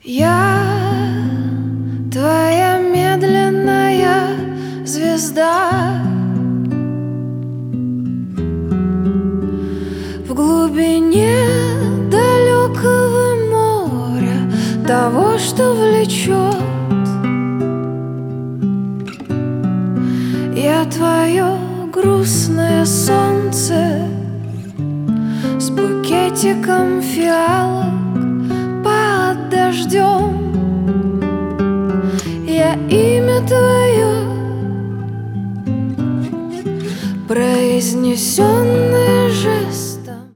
Поп Музыка
кавер # спокойные